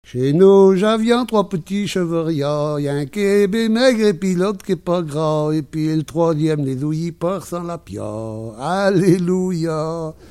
Enquête Arexcpo en Vendée-C.C. Saint-Fulgent
Pièce musicale inédite